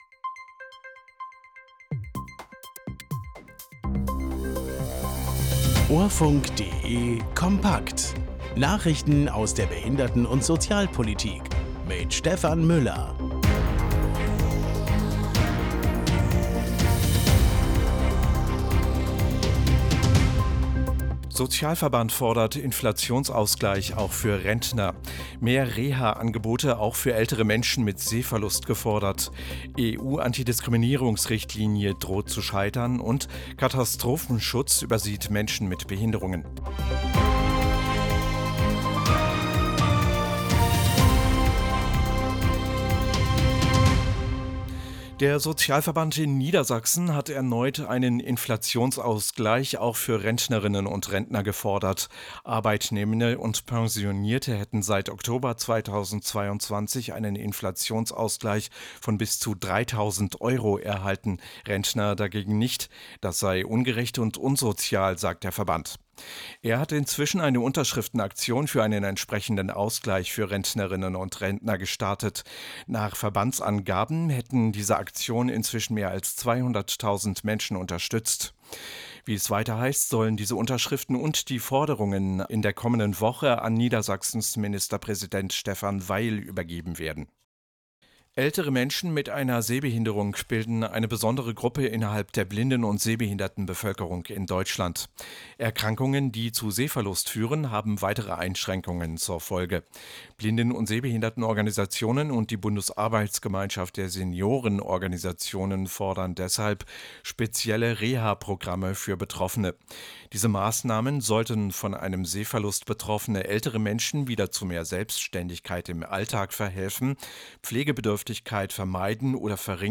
Nachrichten aus der Behinderten- und Sozialpolitik vom 19.06.2024